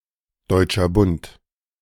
The German Confederation (German: Deutscher Bund [ˌdɔʏtʃɐ ˈbʊnt]